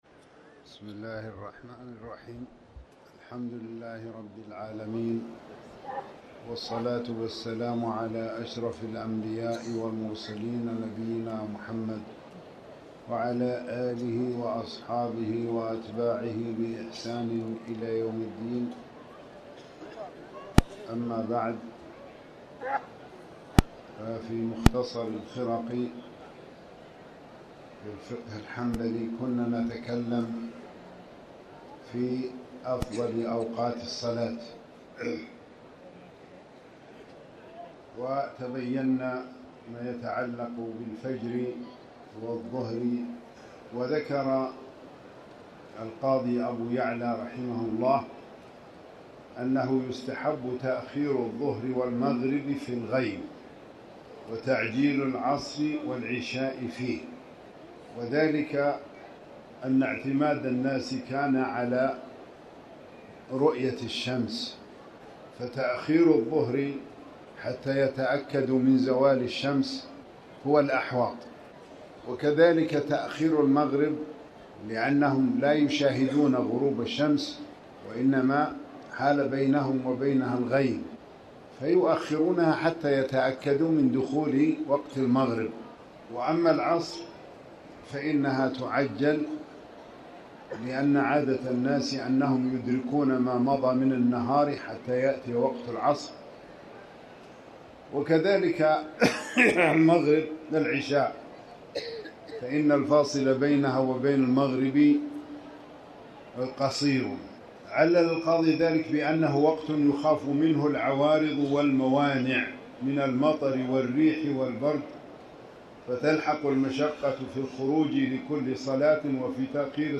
المكان: المسجد الحرام
12rbya-alakhr-wqt-slah-almghrb-walashaa.mp3